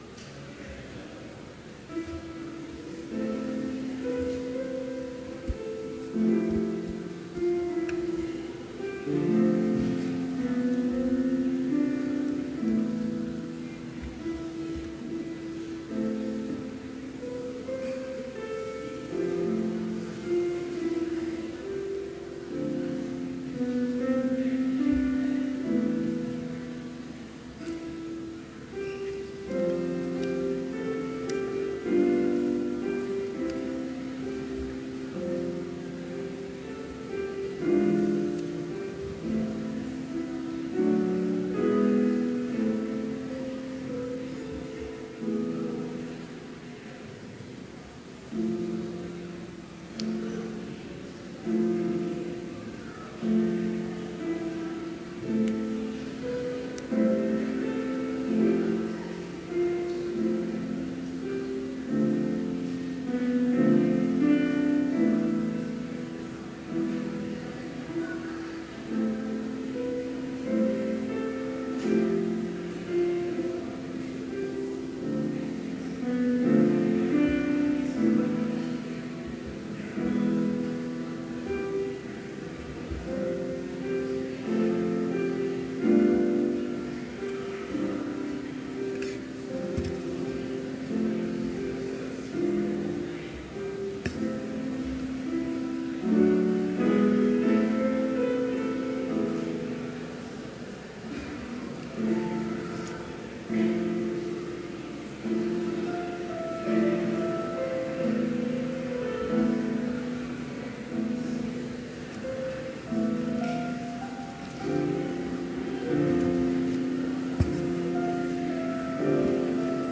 Complete service audio for Chapel - December 8, 2017
Children's Choir